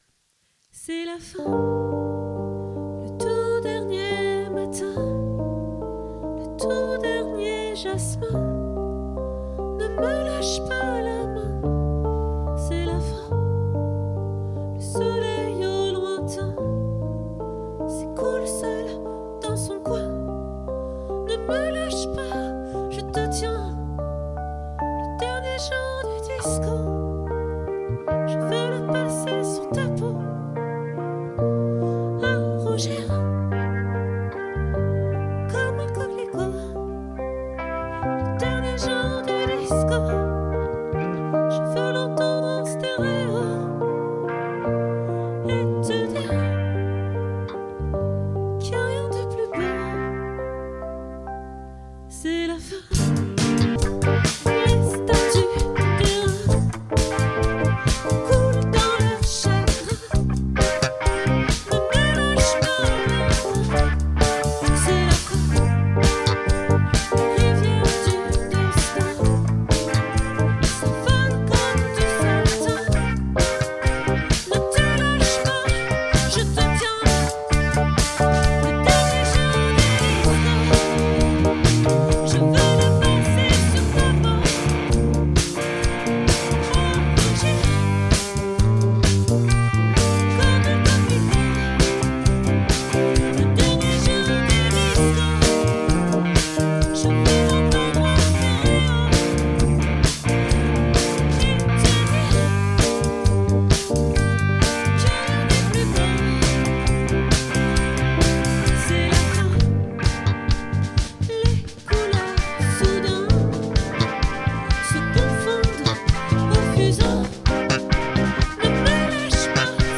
🏠 Accueil Repetitions Records_2025_12_08